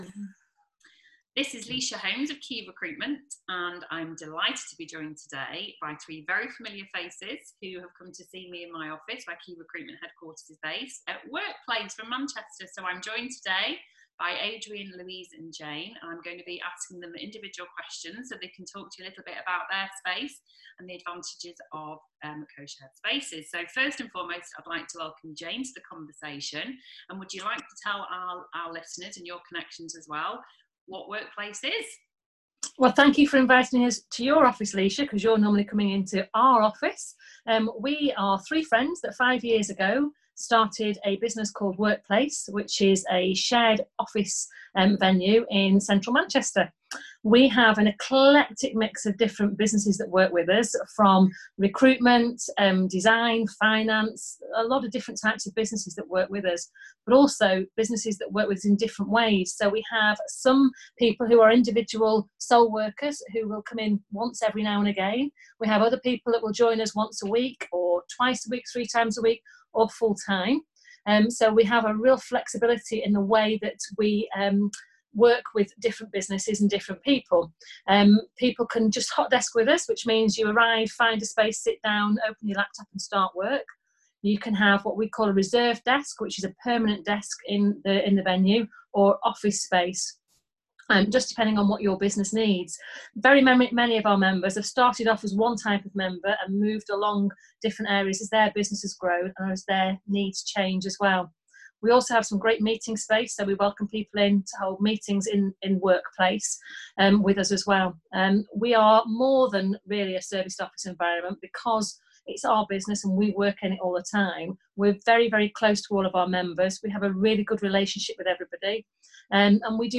workplace-zoom-converted.mp3